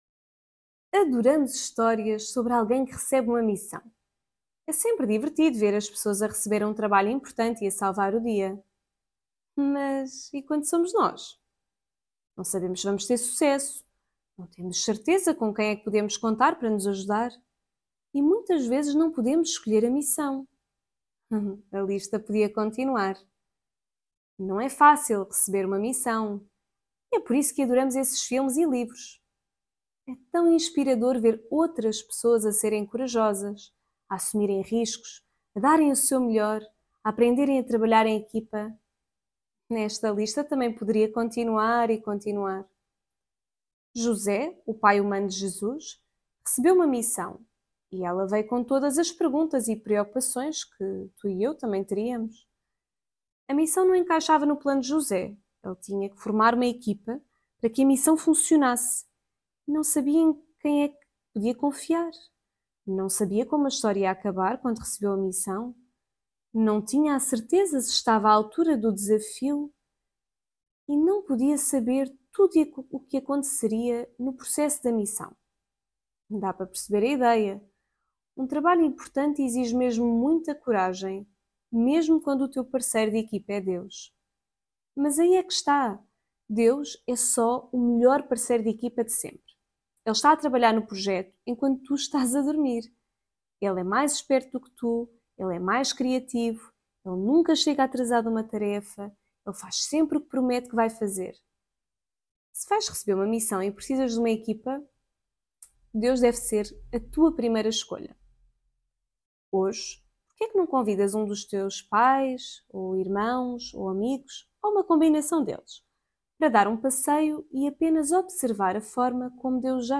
Devocional Coríntios